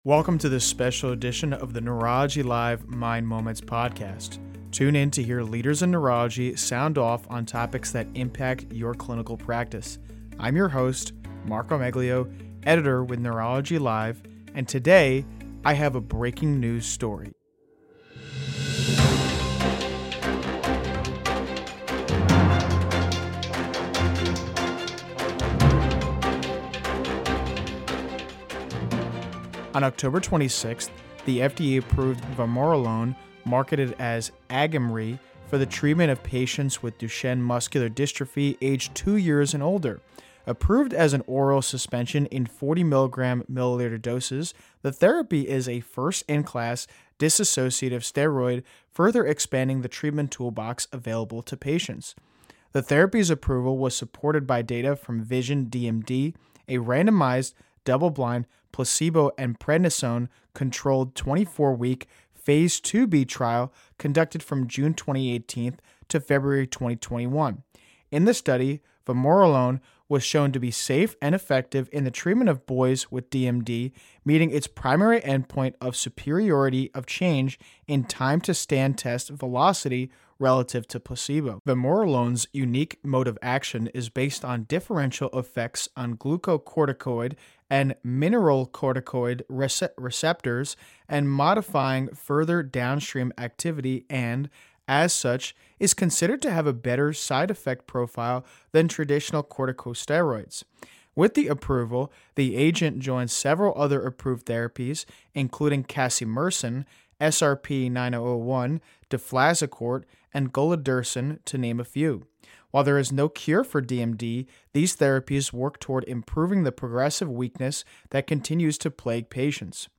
Tune in to hear leaders in neurology sound off on topics that impact your clinical practice. For major FDA decisions in the field of neurology, we release short special episodes to offer a snapshot of...